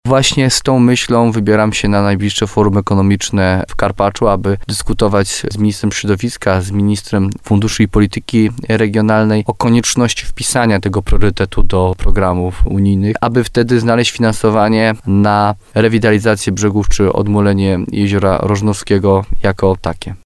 – Ten temat jest dla mnie cały czas aktualny – mówi wójt gminy Gródek nad Dunajcem Jarosław Baziak.